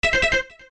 Звуки из игры Супер Марио 64 — SFX